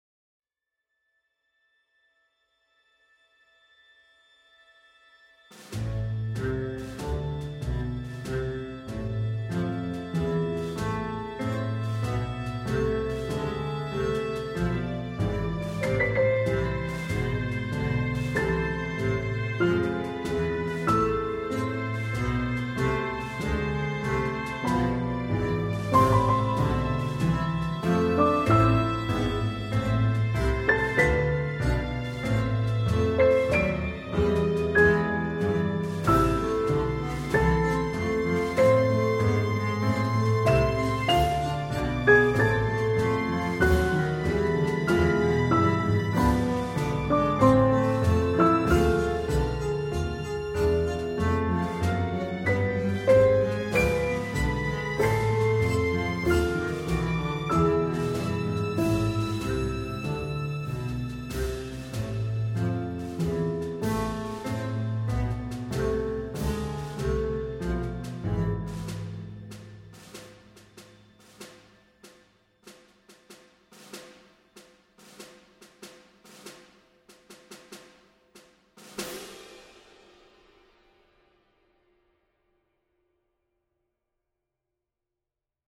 with drums